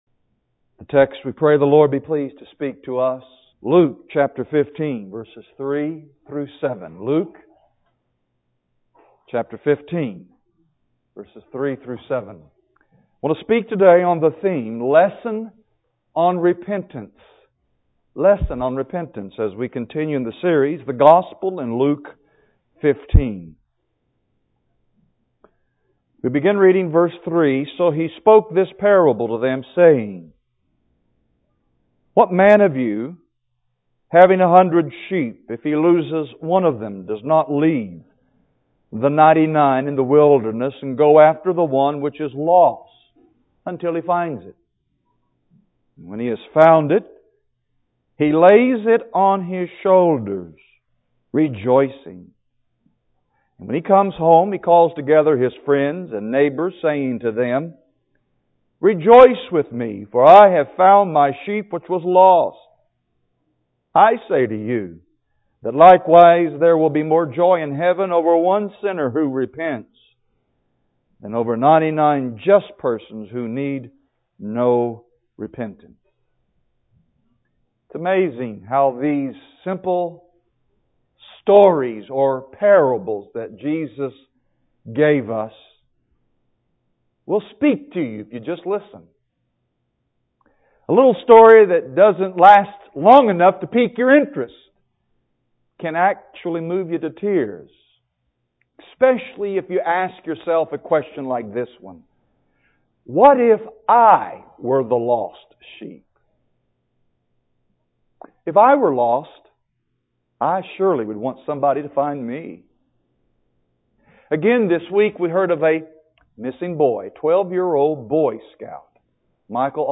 Lesson on Repentance | Real Truth Matters